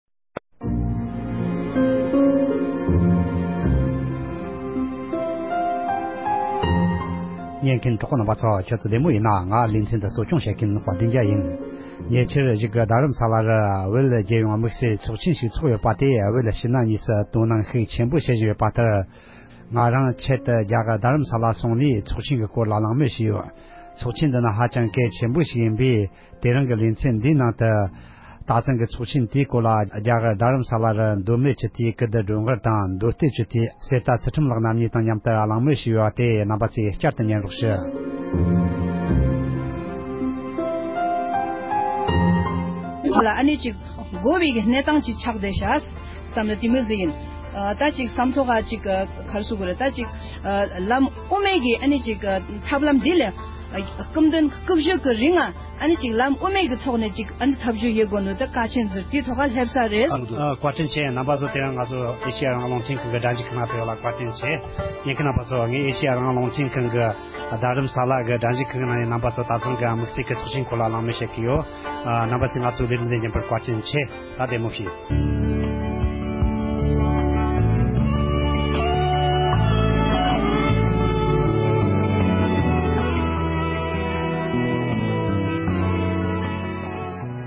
བཞུགས་སྒར་དུ་ཚོགས་ཡོད་པའི་བོད་དོན་དམིགས་བསལ་ཚོགས་ཆེན་གྱི་ཉིན་ལྔ་པར་ཚོགས་བཅར་བ་ཁག་དང་ལྷན་དུ་ཚོགས་ཆེན་སྐོར་གླེང་མོལ།
སྒྲ་ལྡན་གསར་འགྱུར།